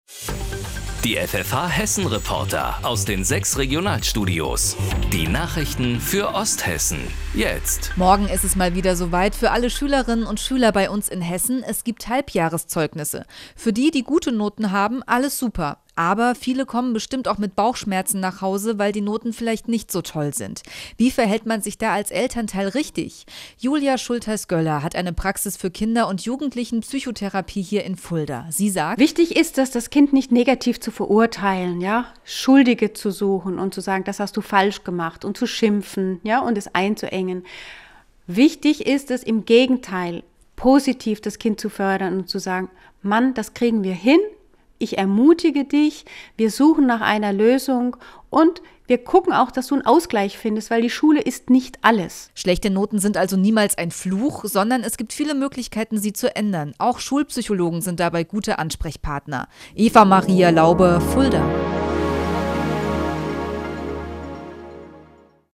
FFH – Interview